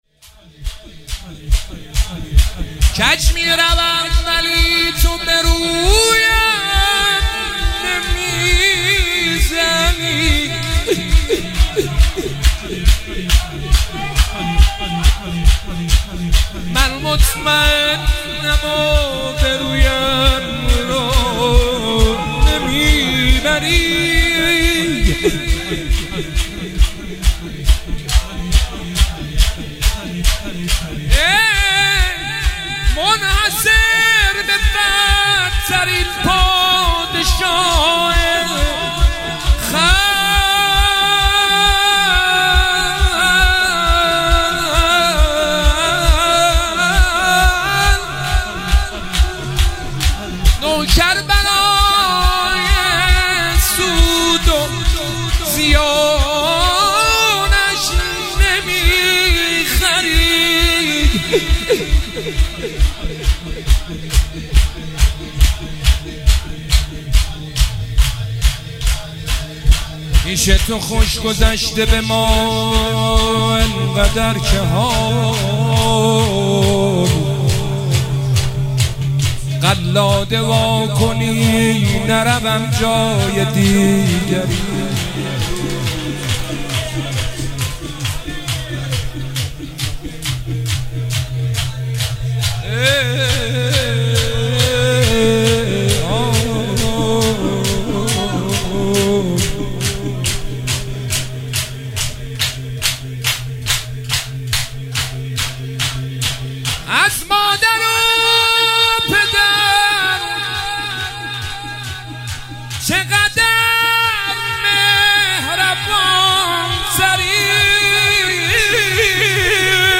هیئت هفتگی 27 اردیبهشت 1404